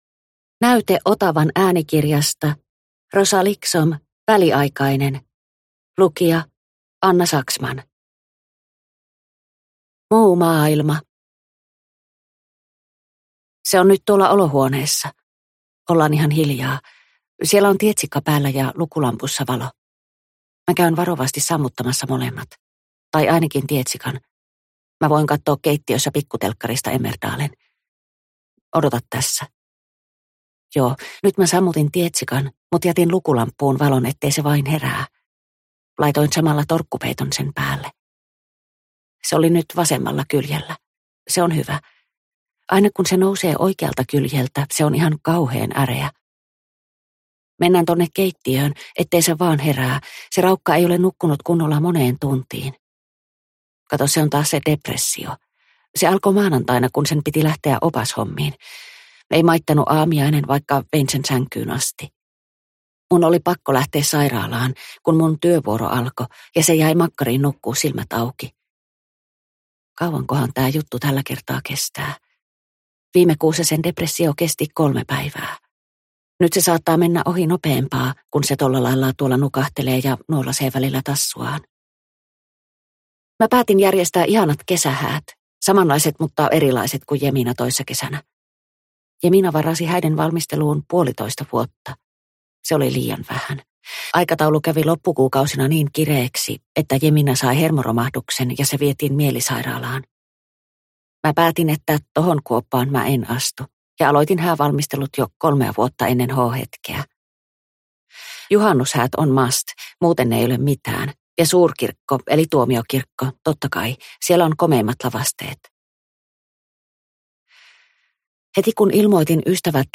Väliaikainen – Ljudbok – Laddas ner